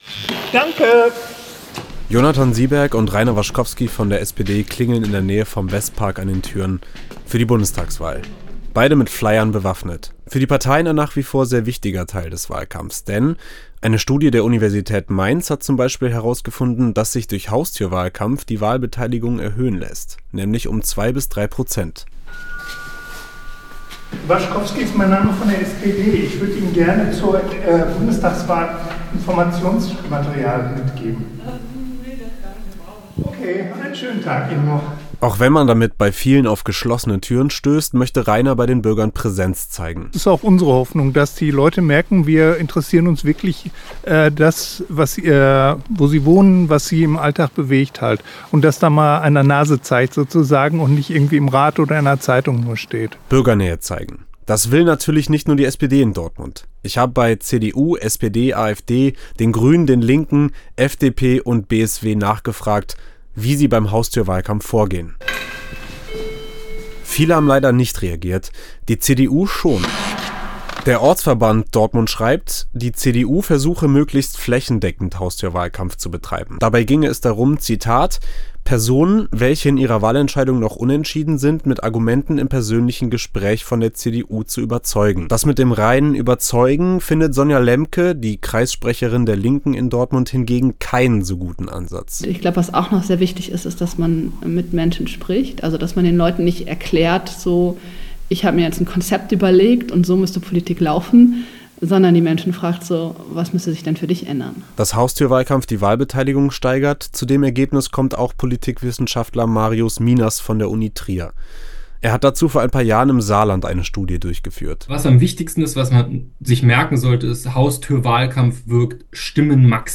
Haustürwahlkampf - unterwegs mit einer Partei im Wahlkampf